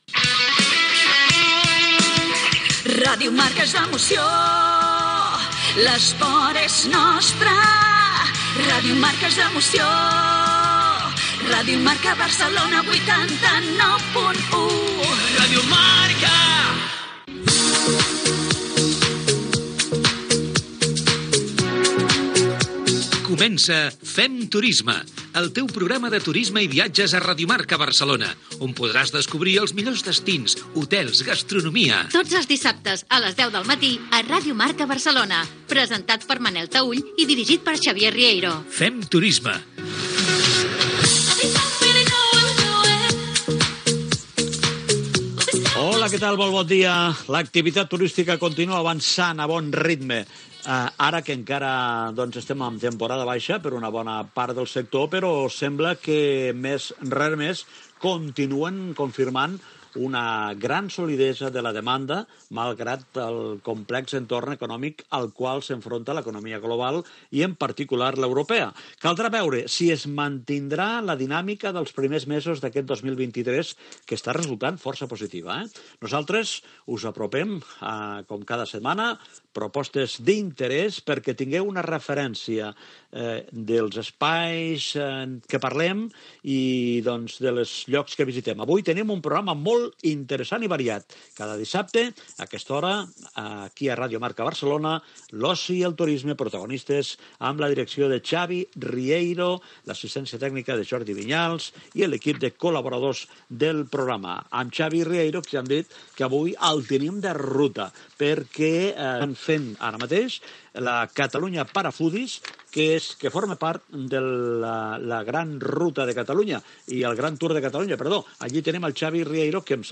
Indicatiu de l'emissora, careta del programa, comentari sobre la demanda turística
Divulgació
FM